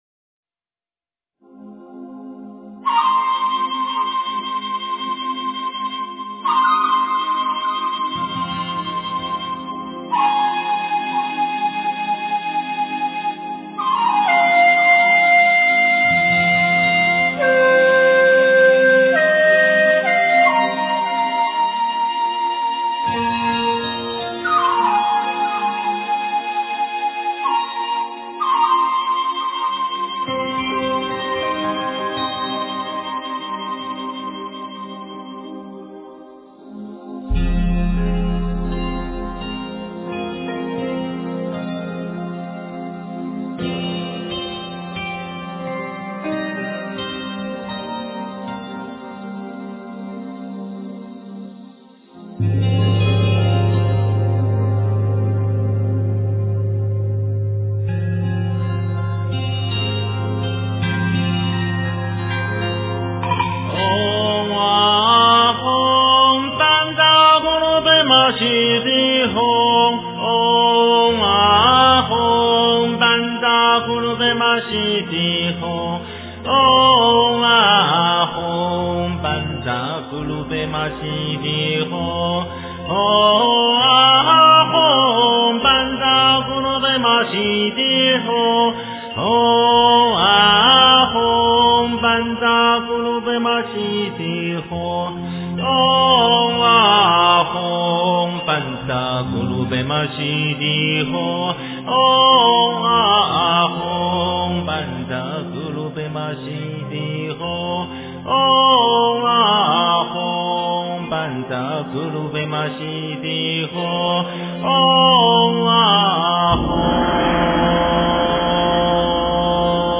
诵经
佛音 诵经 佛教音乐 返回列表 上一篇： 大悲咒-笛子版 下一篇： 忏悔文 相关文章 大吉祥天女咒--黑鸭子 大吉祥天女咒--黑鸭子...